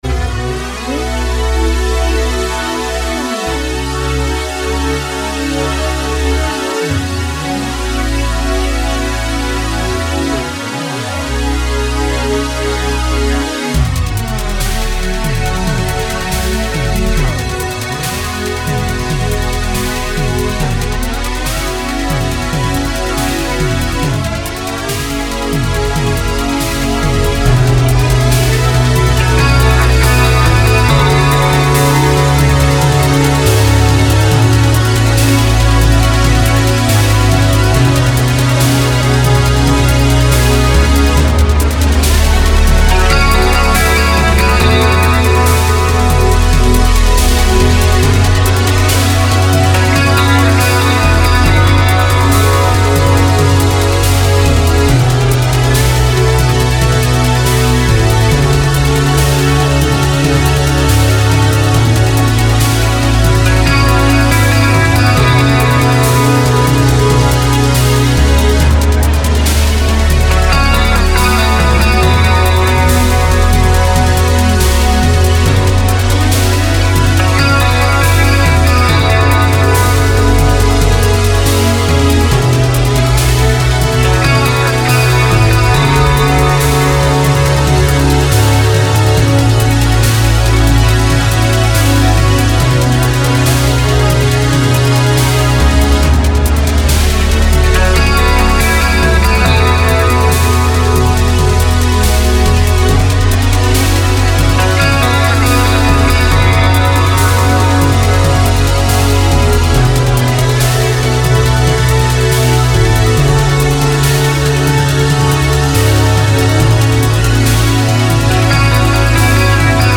Hello everybody, here's a nice track to chill to.